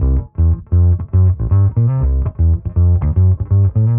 Index of /musicradar/dusty-funk-samples/Bass/120bpm
DF_PegBass_120-C.wav